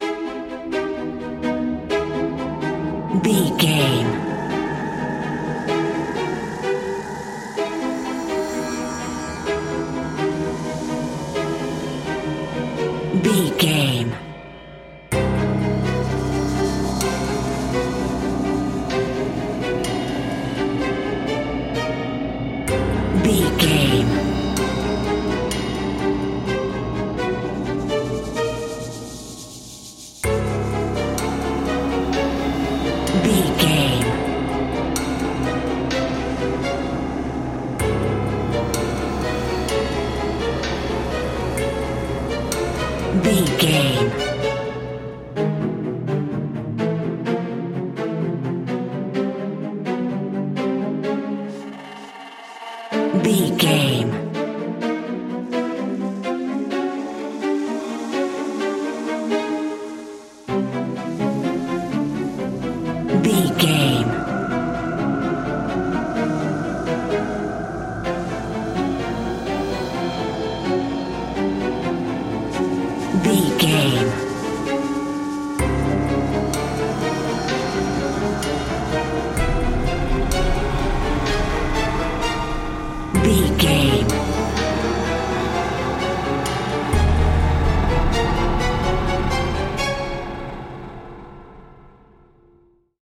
Atonal
ominous
drone
eerie
strings
synth
percussion
medium tempo